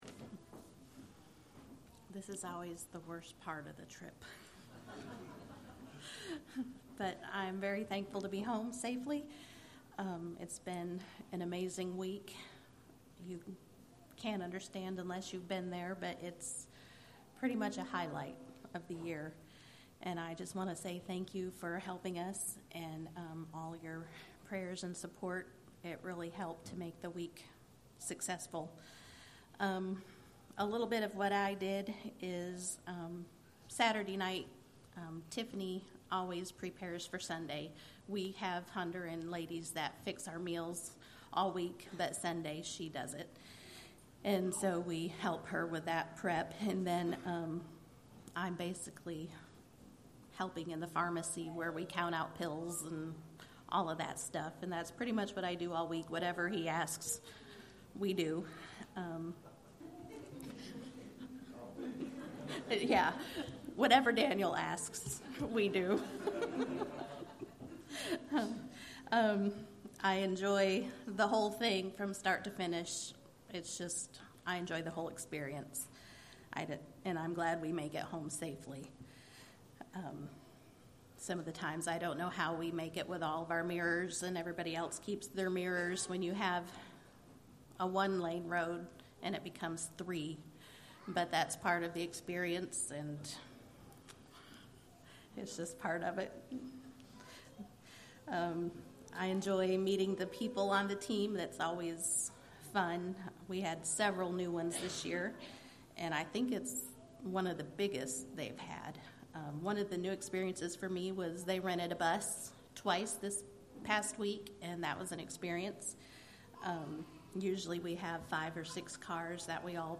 Report from those who went to Honduras as part of the EFM Medical Brigade in October 2025